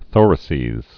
(thôrə-sēz)